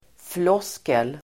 Ladda ner uttalet
Folkets service: floskel floskel substantiv, high-sounding empty phrase Uttal: [fl'ås:kel] Böjningar: floskeln, floskler Definition: vackert men innehållslöst uttryck Exempel: tomma floskler (empty rhetoric)
floskel.mp3